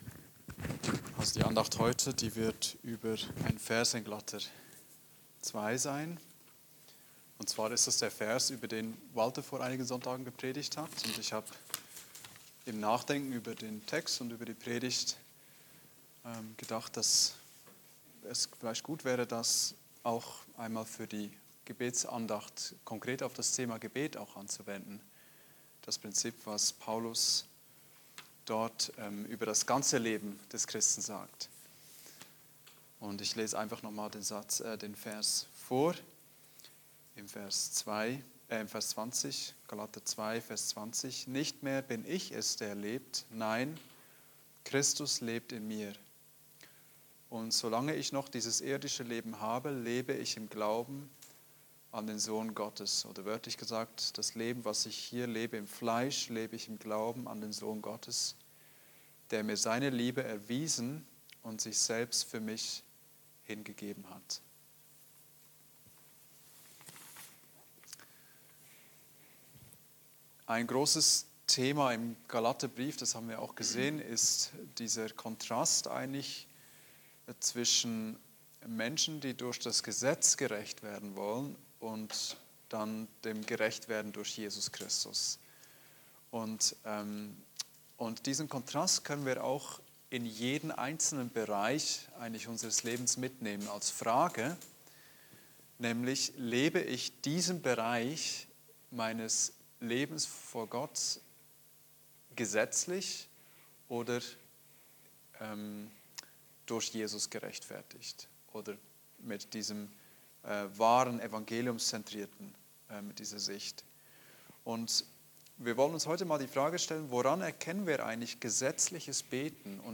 Gesetzlichkeit und Bekenntnis (Andacht Gebetsstunde)